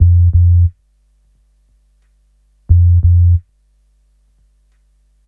HP089BASS1-R.wav